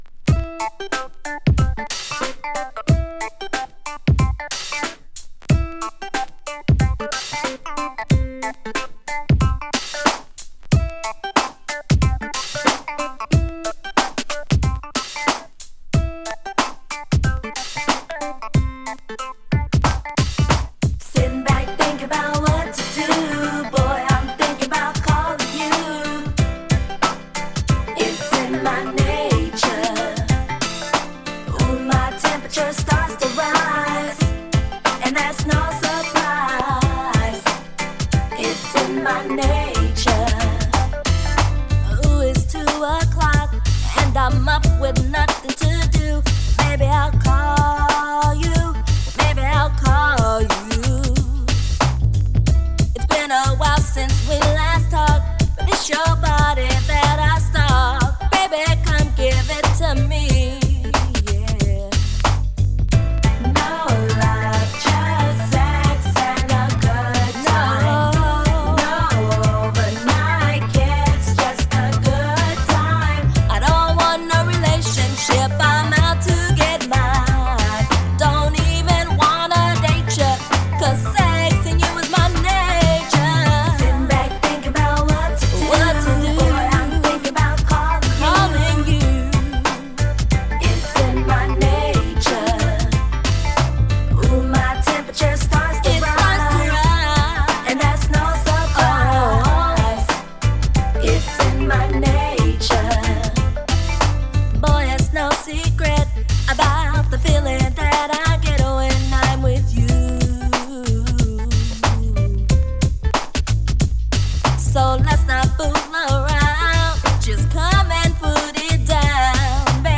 HIP HOP SOUL人気曲。